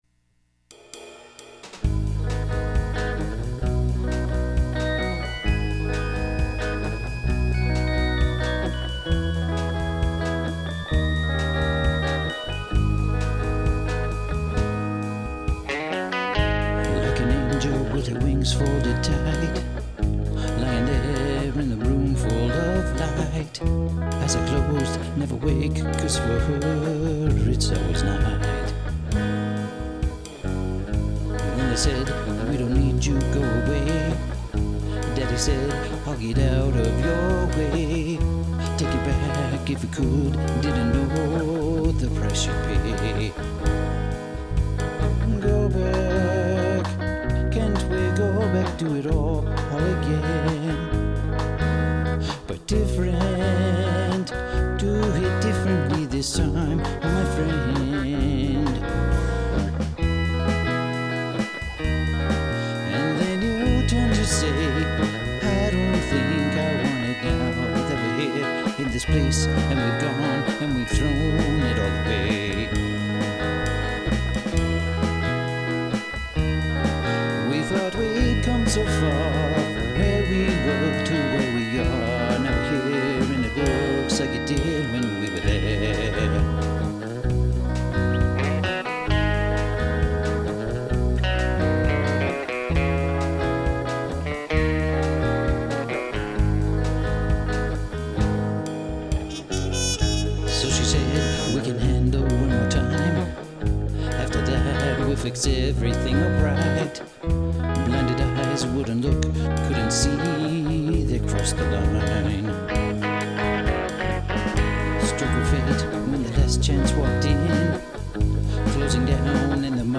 Vocals feel a little loud and crisp at first, but they settle in more as more instruments come in.
It's kinda buried.
What -The Farfisa style keyboard may be a little loud.
I think you could add a touch of brightness to the vocal ..... it's kinda muffled sounding to my ears ....... drumbs aren't very present ...... ... nice tune
I visualize a smoky little bar with a little band playing.
I do think the drums could be a little more out front, the snare is a little dull for my taste. And some of the lead guitar fills are a bit loud.
Also, there's a snare hit at 1:23 which I don't think should be there.
The organ thing is a tiny bit piercing and doesn't play nice with the vocal - move it back I reckon.